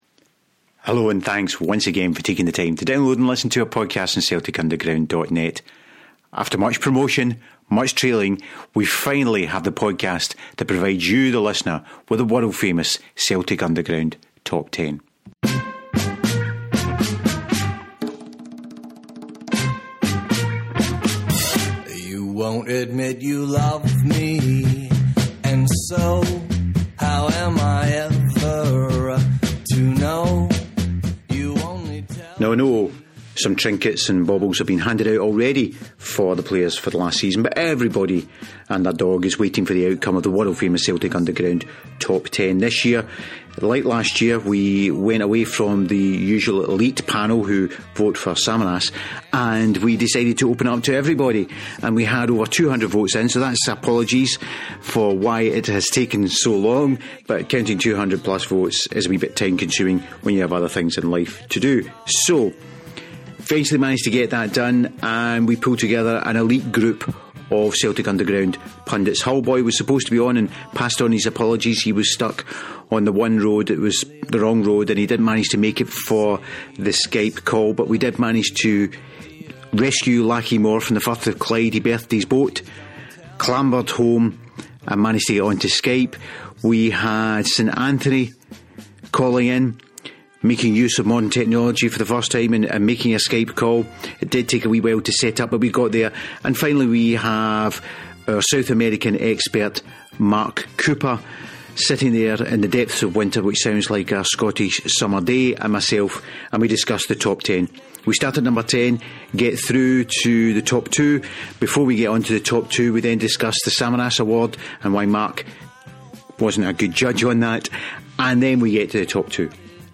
We pulled together some of the team and we all sat around a Skype mic and ran through that ten.